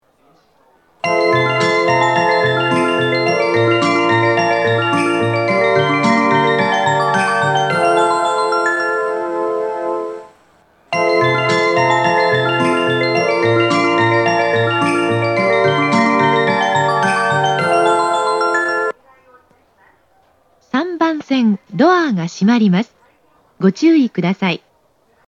発車メロディー
1.9コーラスです。